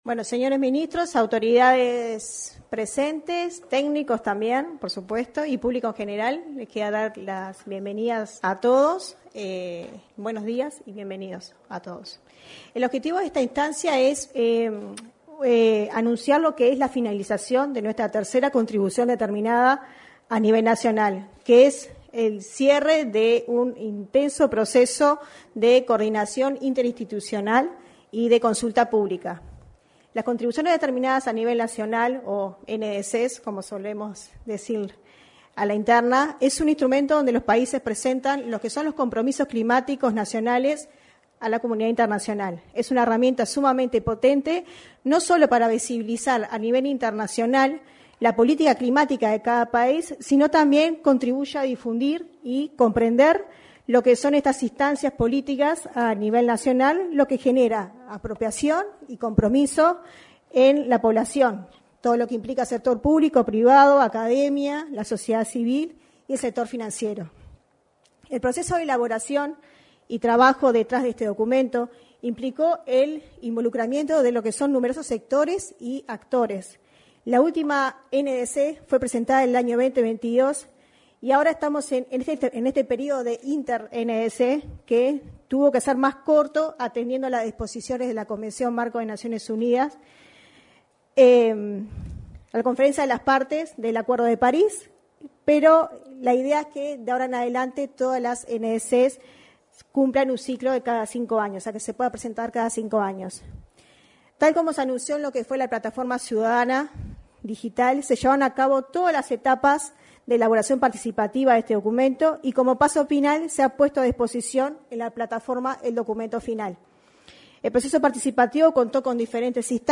Presentación de la 3.ª Contribución Determinada a Nivel Nacional 17/12/2024 Compartir Facebook X Copiar enlace WhatsApp LinkedIn En el salón de actos de la Torre Ejecutiva, se desarrolló, este martes 17, la presentación de la 3.ª Contribución Determinada a Nivel Nacional. En la oportunidad, se expresaron la directora nacional de Cambio Climático del Ministerio de Ambiente, Natalie Pareja, y los ministros de Ganadería, Agricultura y Pesca, Fernando Mattos; Industria, Energía y Minería, Elsa Facio; Vivienda y Ordenamiento Territorial, Raúl Lozano; Economía y Finanzas, Azucena Arbeleche; Relaciones Exteriores, Omar Paganini, y Ambiente, Robert Bouvier.